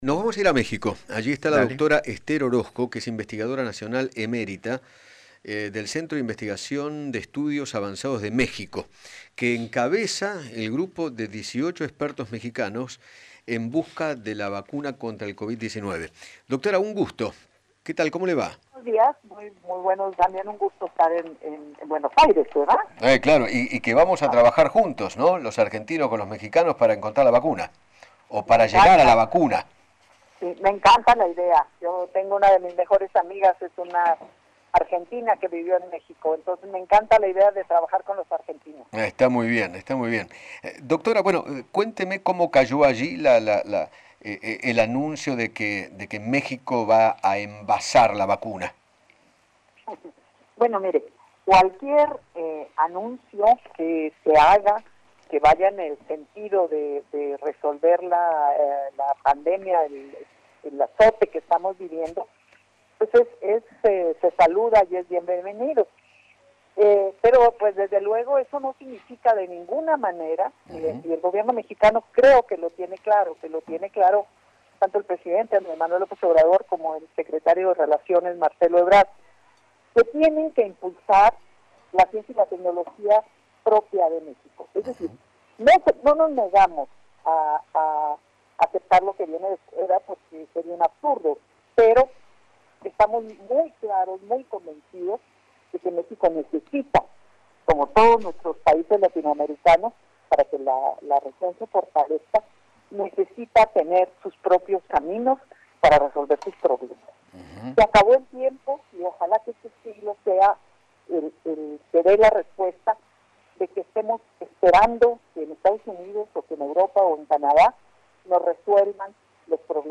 dialogó con Eduardo Feinmann sobre el impacto del acuerdo con Argentina en México para producir la vacuna contra el Covid-19 y sostuvo que “se acabó el tiempo de estar esperando que desde Estados Unidos